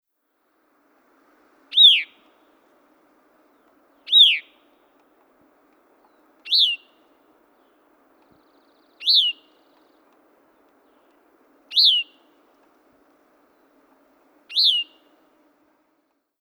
Звуки дрозда
Пение звонкоголосого дрозда в утренней тишине